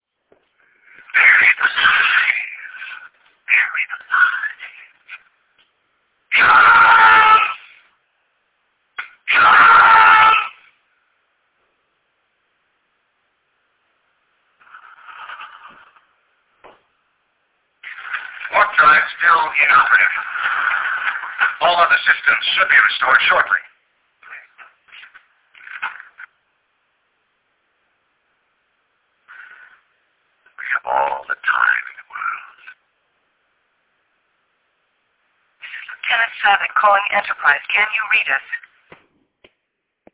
Quality is low, but the rarity of these materials makes them an absolute treasure.